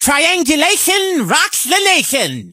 carl_start_vo_07.ogg